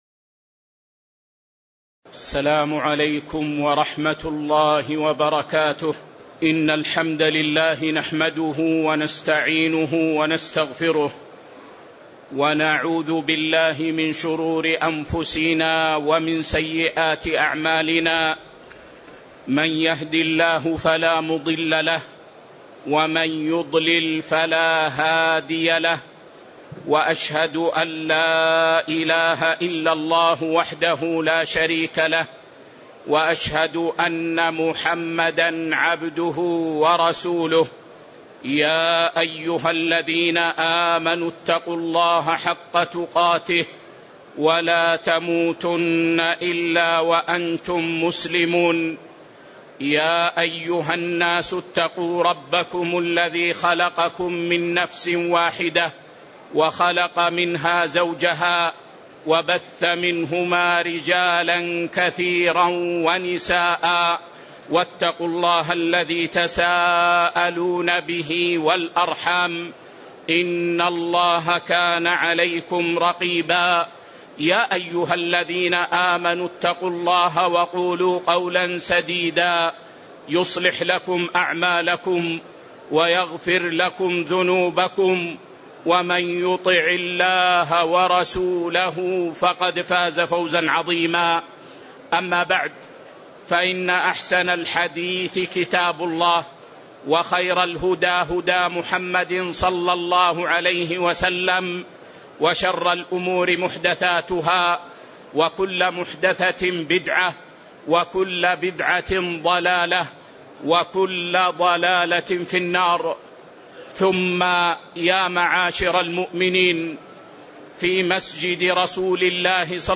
تاريخ النشر ١٧ رمضان ١٤٤٤ هـ المكان: المسجد النبوي الشيخ